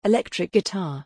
Electric guitar | 7,111 of 14,400